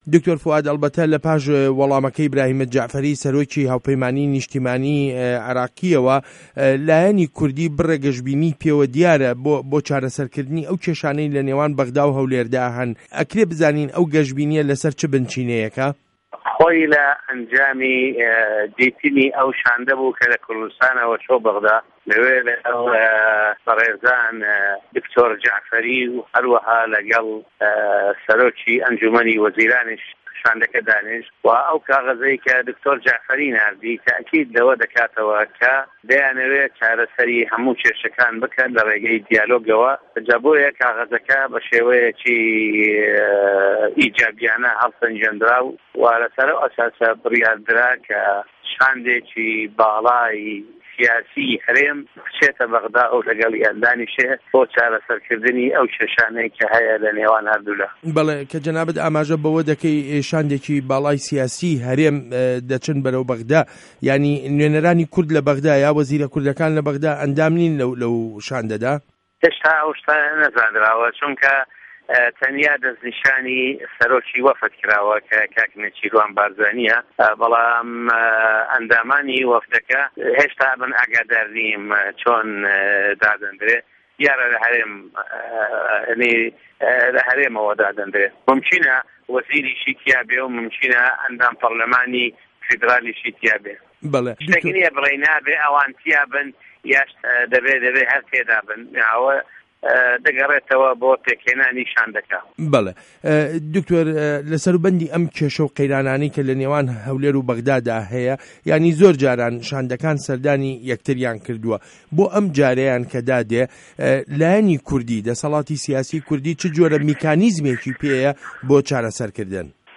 وتووێژ له‌گه‌ڵ دکتۆر فوئاد مه‌عسوم